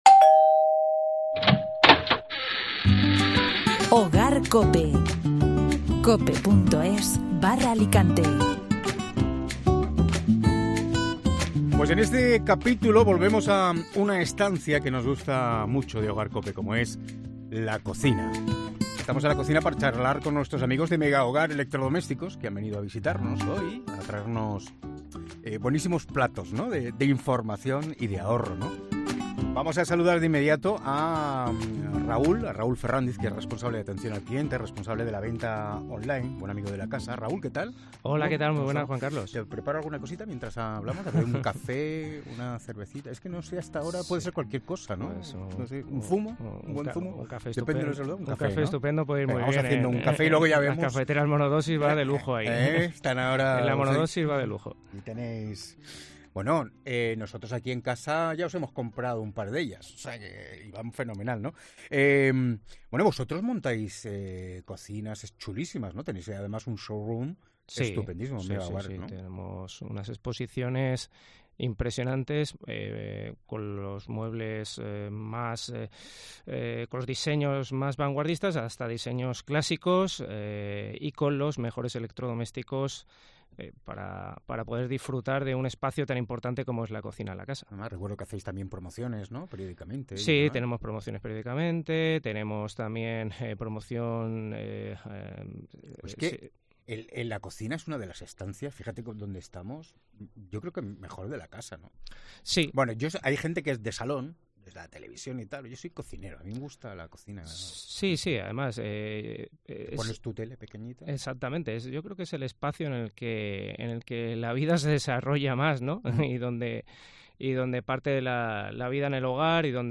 Megahogar es una de las marcas más reconocidas en Alicante cuando hablamos de cocinas y electrodomésticos. En esta entrevista conocemos las últimas tendencias y las principales novedades.